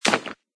plasticstone3.mp3